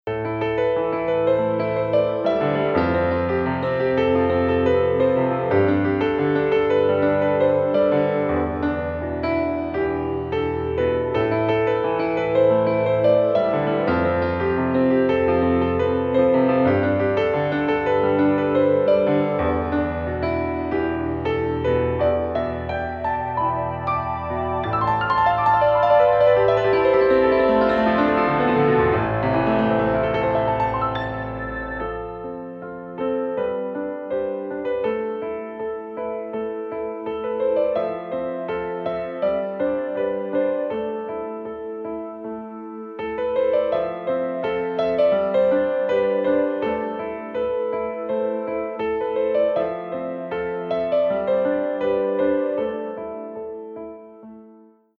Instrumentalstück - ohne Gesang.